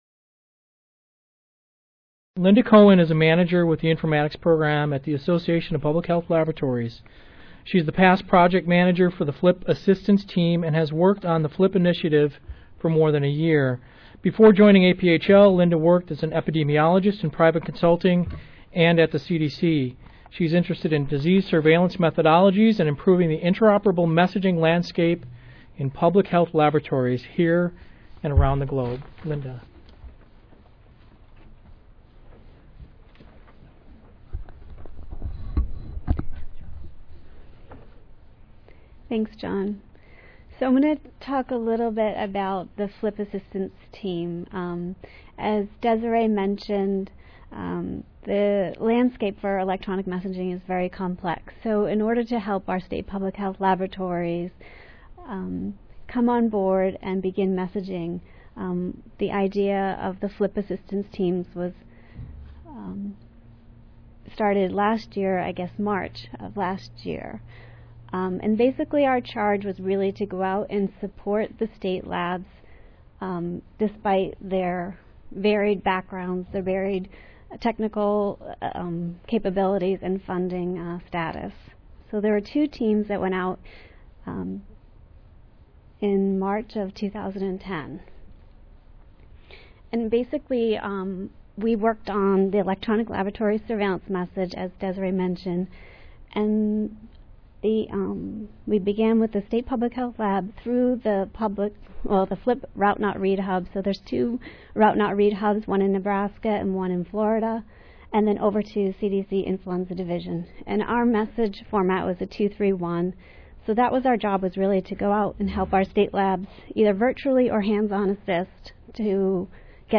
This panel presentation describes the processes and products achieved through the collaborative efforts of the Association of Public Health Laboratories (APHL) and the Centers for Disease Control and Prevention (CDC) joint project; the Public Health Laboratory Interoperability Project (PHLIP).
Moderator:
Panelists: